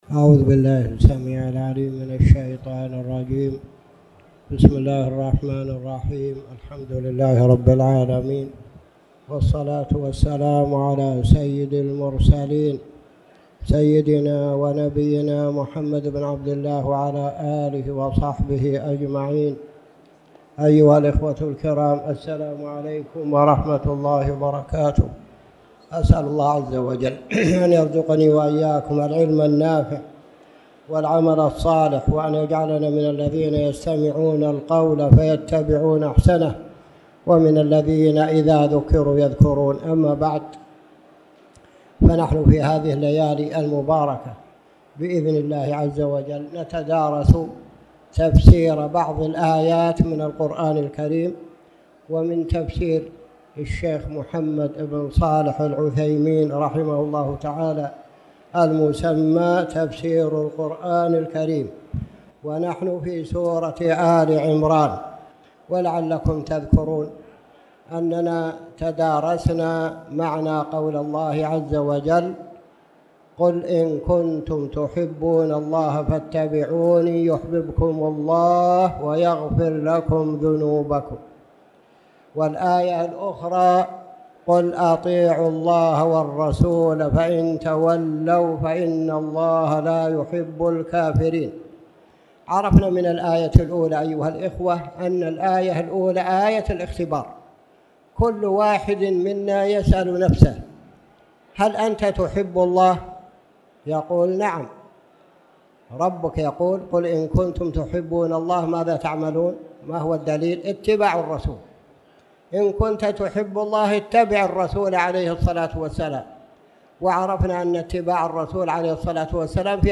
تاريخ النشر ٩ جمادى الأولى ١٤٣٨ هـ المكان: المسجد الحرام الشيخ